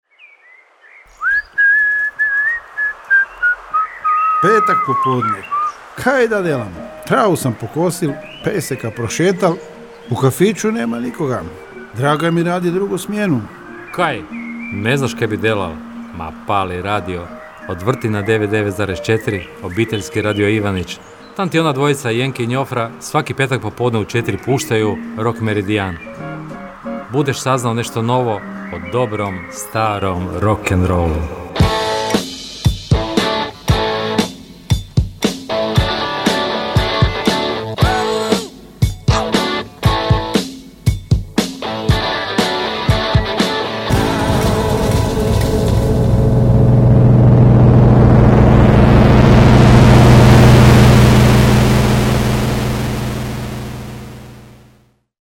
AUDIO JINGLE: